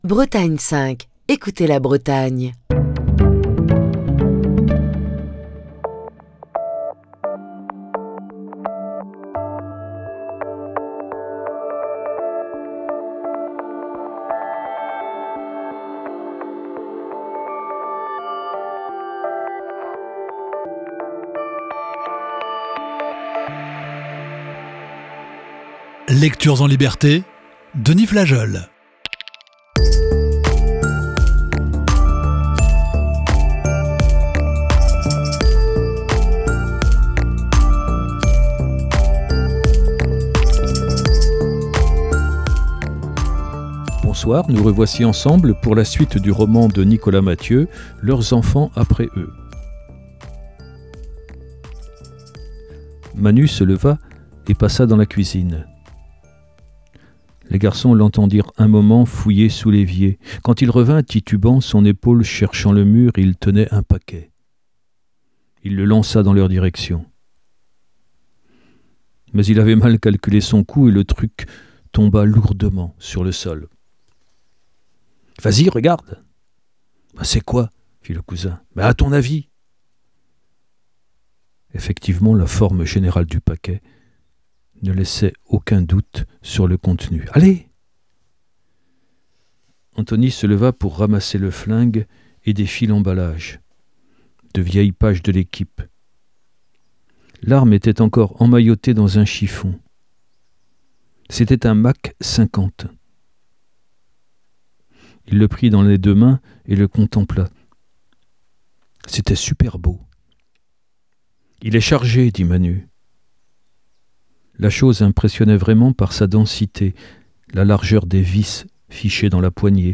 Lecture(s) en liberté